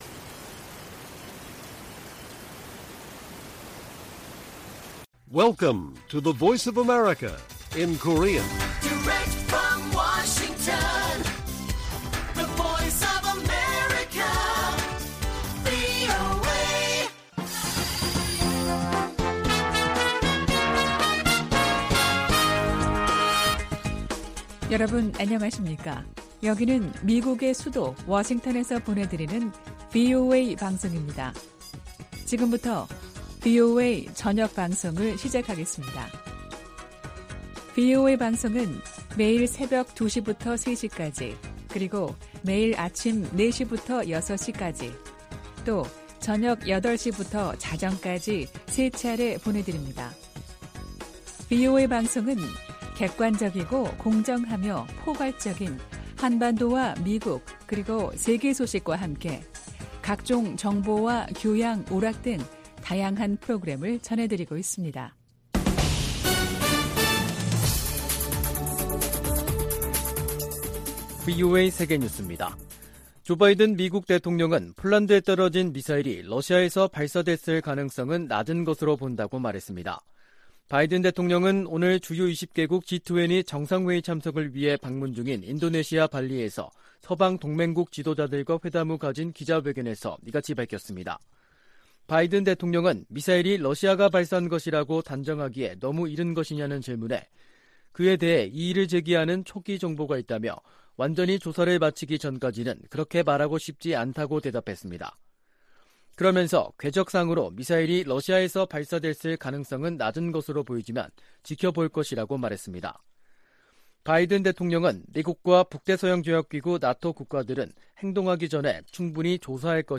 VOA 한국어 간판 뉴스 프로그램 '뉴스 투데이', 2022년 11월 16일 1부 방송입니다. 도널드 트럼프 전 미국 대통령이 2024년 대통령 선거에 다시 출마할 것이라고 공식 발표했습니다. 한국과 중국 두 나라 정상이 15일 열린 회담에서 북한 문제에 대해 논의했지만 해법을 놓고는 기존 시각차를 확인했을 뿐이라는 평가가 나오고 있습니다.